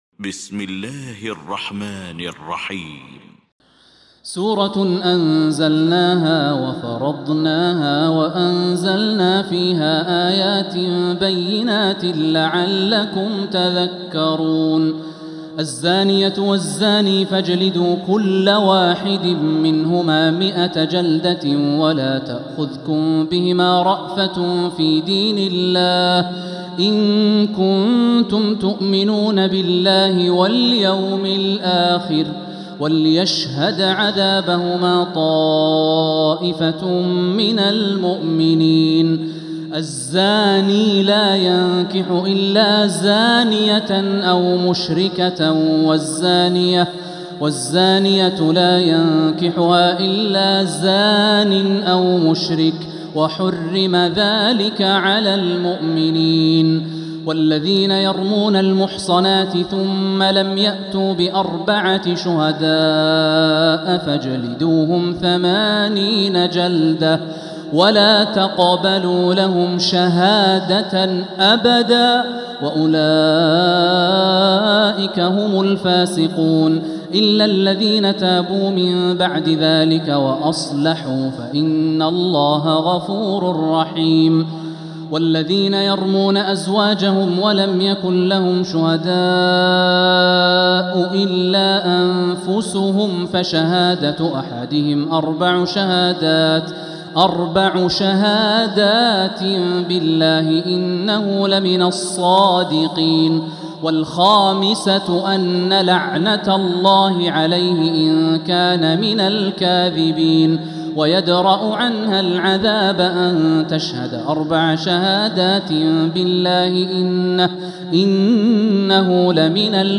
المكان: المسجد الحرام الشيخ: فضيلة الشيخ د. الوليد الشمسان فضيلة الشيخ د. الوليد الشمسان فضيلة الشيخ ماهر المعيقلي النور